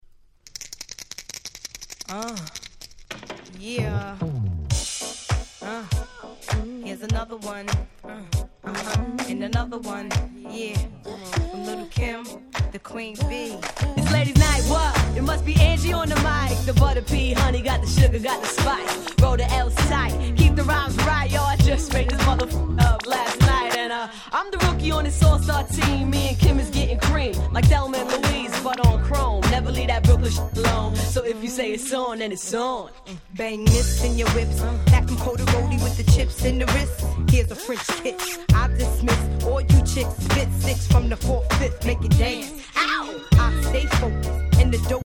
Brooklyn Classic !!